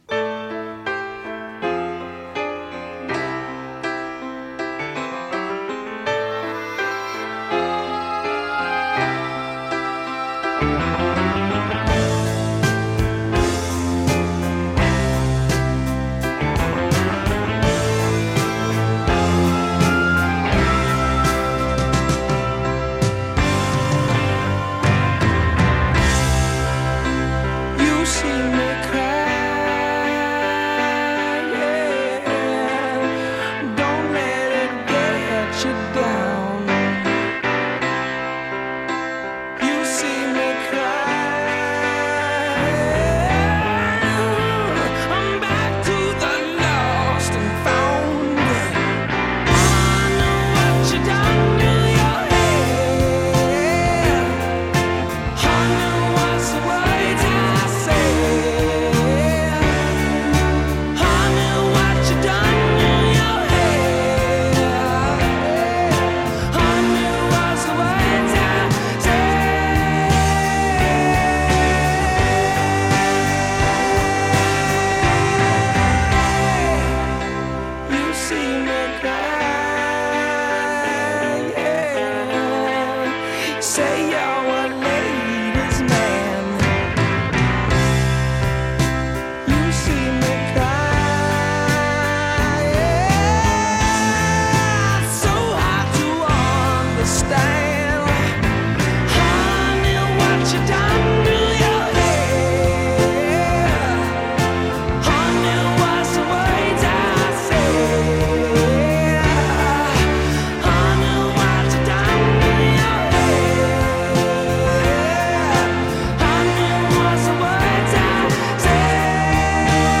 power ballad